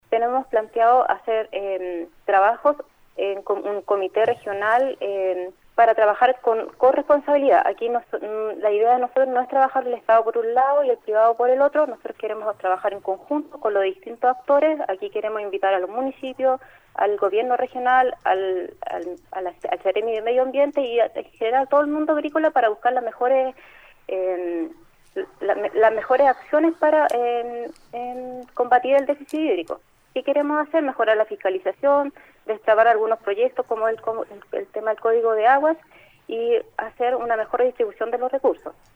En conversación con Nuestra Pauta, indicó que «hemos estado en varias comunas, escuchando y viendo la realidad, para empezar ya a generar las políticas que la gente necesita».